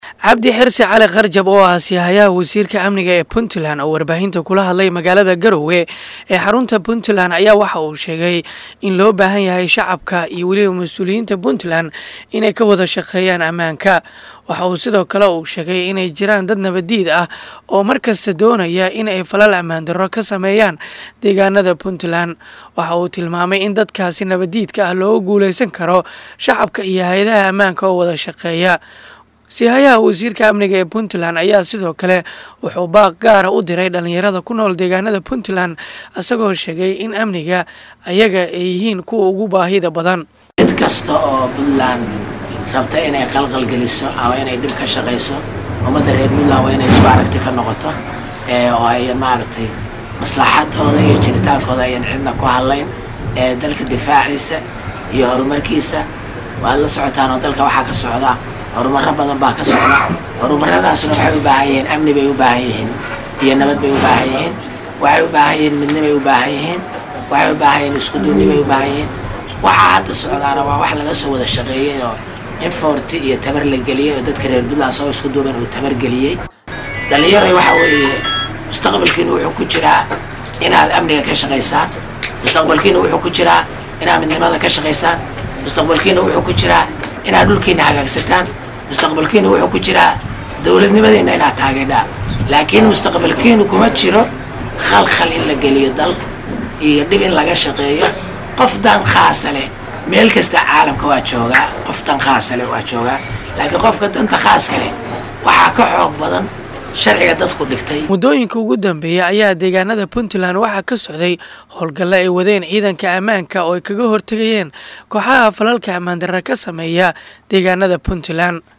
Mudane Cabdi Xirsi Cali Qarjab oo ah sii hayaha Wasiirka Amniga Puntland oo warbaahinta kula hadlay magaalada Garoowe ayaa sheegay